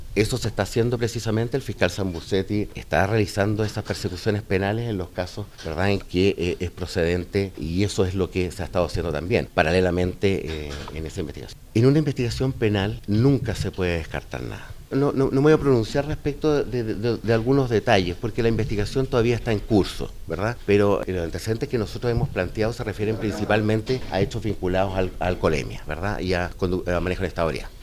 El fiscal regional Marcos Emilfork expresó que están trabajando para perseguir a las personas que están involucradas en el delito.